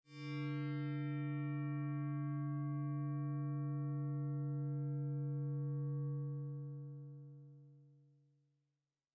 描述：通过Modular Sample从模拟合成器采样的单音。
Tag: CSharp5 MIDI音符-73 Korg的-Z1 合成器 单票据 多重采样